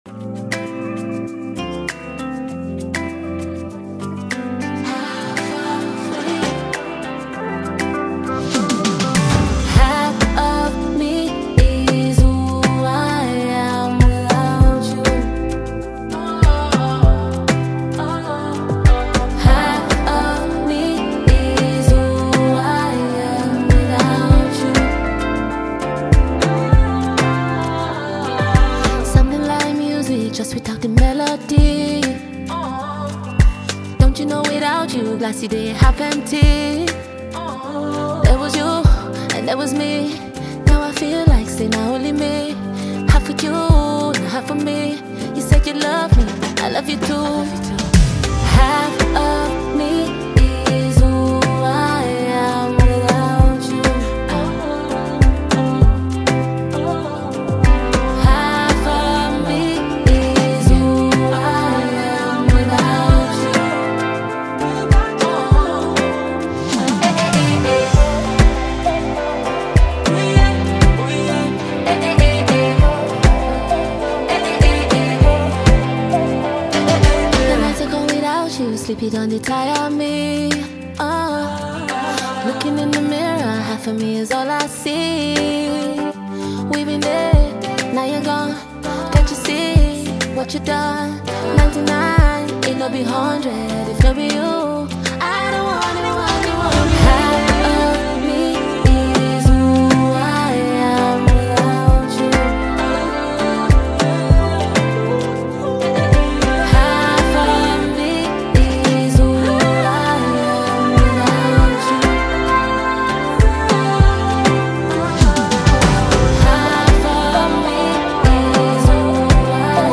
Nigerian singer-songwriter and talented artist
soulful and heartfelt music